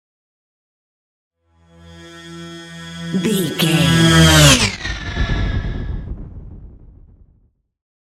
Sci fi ship vehicle pass by
Sound Effects
futuristic
pass by